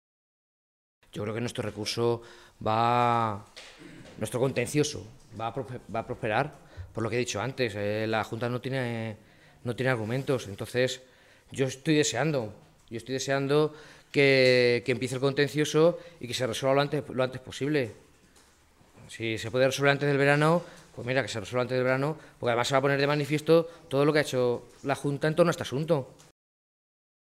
Jesús Fernández Clemente, alcalde de Tembleque
Cortes de audio de la rueda de prensa